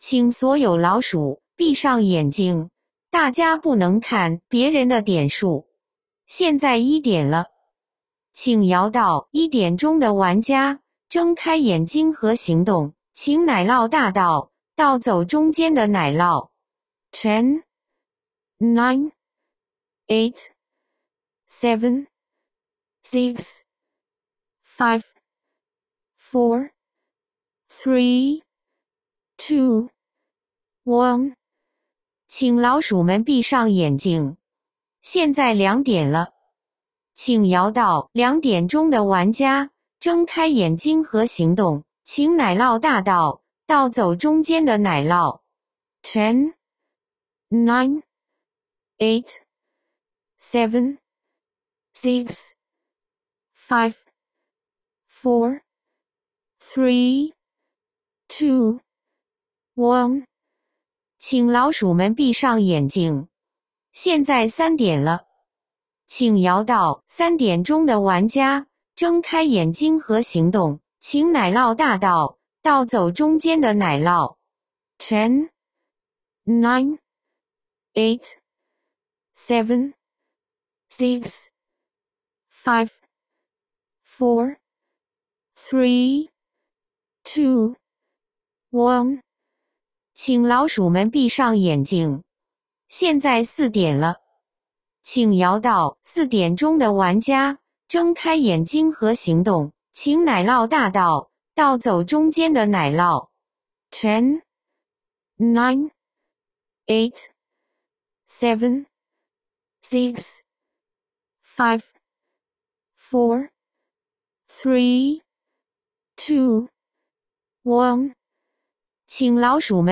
奶酪大盗桌游主持人配音，按 4–8 人局选择，可直接播放与下载。
cheese_thief_4p_host.m4a